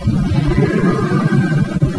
EVP 1: Keep Away